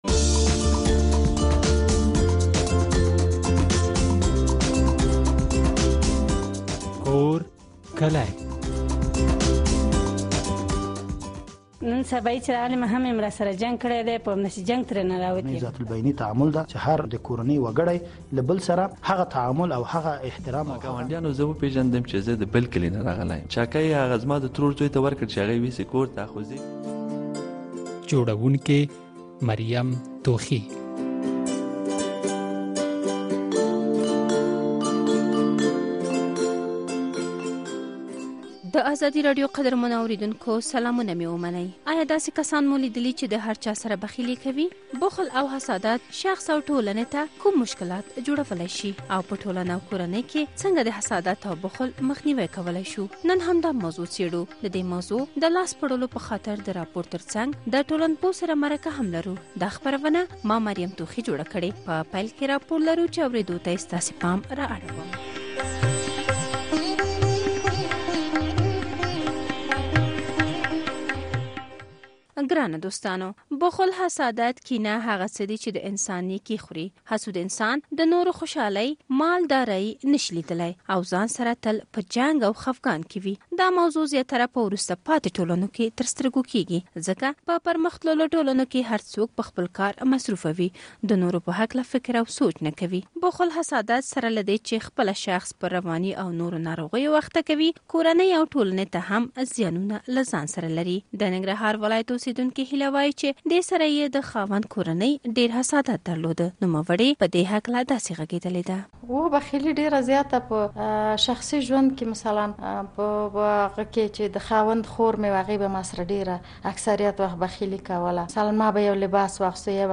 نن په دې خپرونه کې همدا موضوع څېړل شوې، د دې موضوع د لاڅېړلو په خاطر د راپور ترڅنګ د ټولنپوه سره مرکه هم شوې ده.